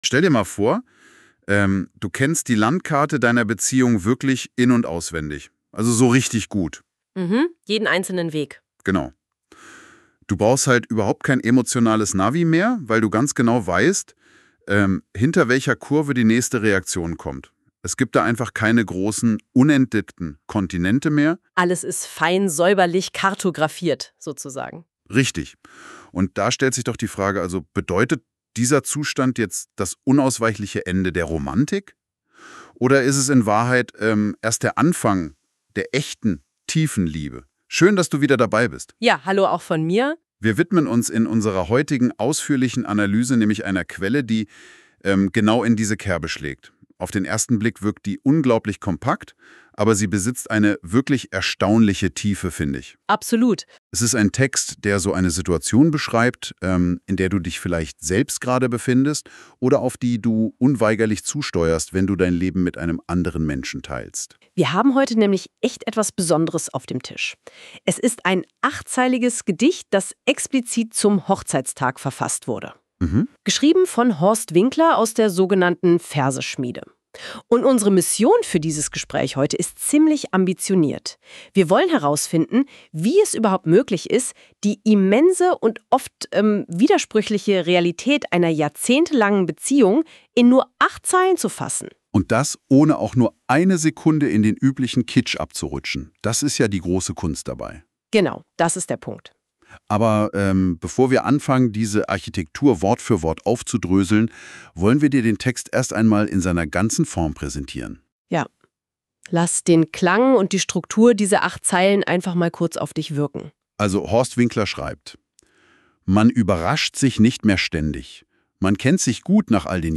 MP3-Podcast KI-Rezension Podcast mit GOOGLE-Konto